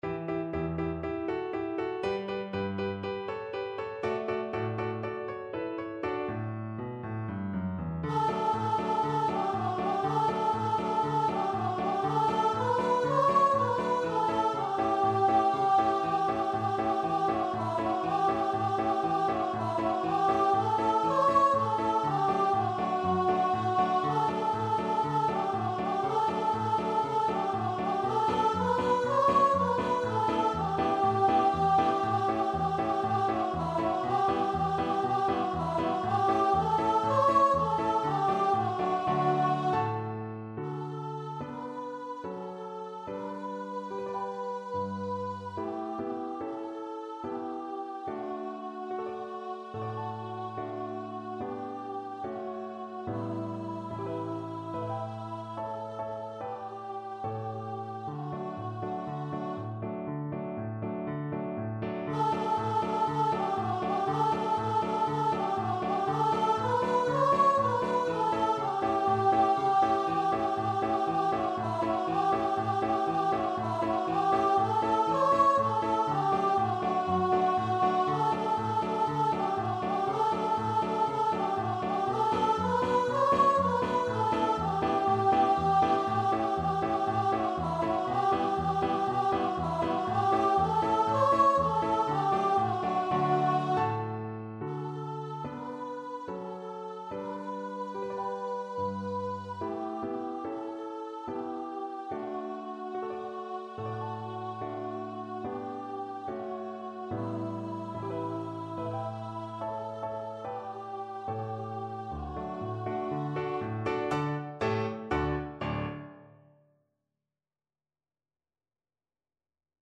Voice
Traditional Music of unknown author.
D major (Sounding Pitch) (View more D major Music for Voice )
4/4 (View more 4/4 Music)
Allegro (View more music marked Allegro)